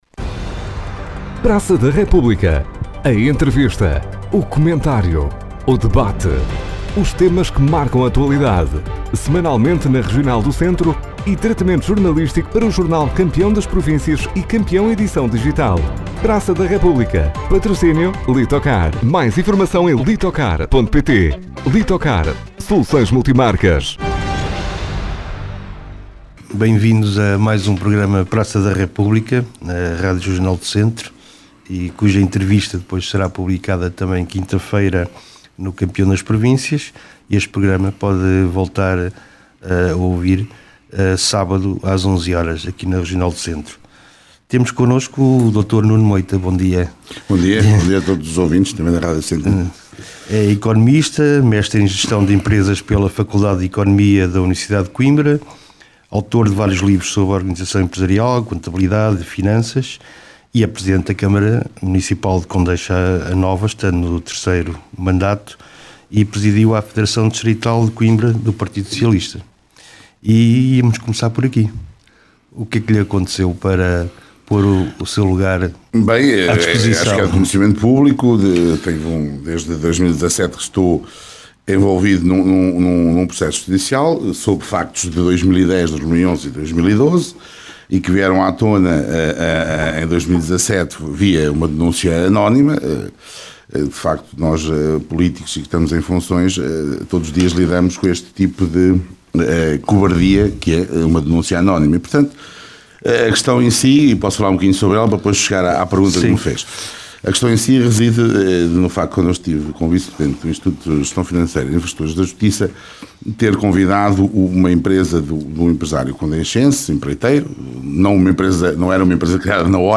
Na edição desta semana do ‘Praça da República’, a entrevista a Nuno Moita, presidente da Câmara Municipal de Condeixa-a-Nova.
Praça da República – Entrevista a Nuno Moita